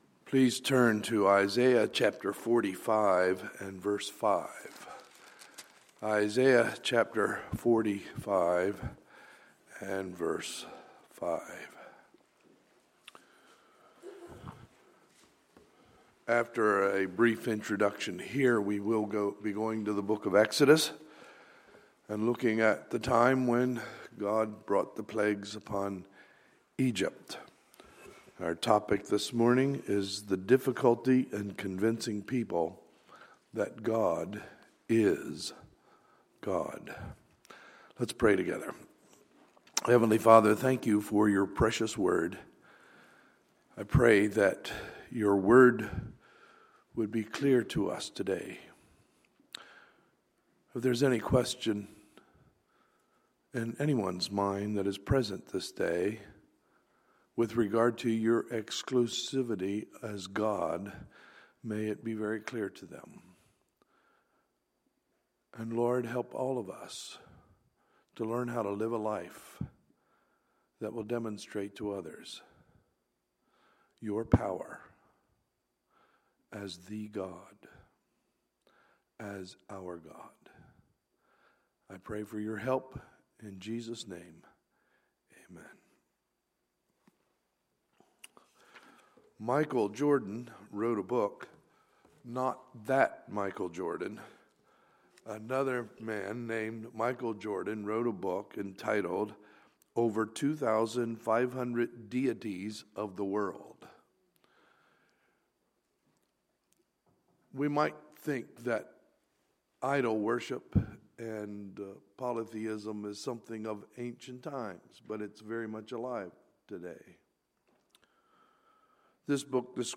Sunday, April 24, 2016 – Sunday Morning Service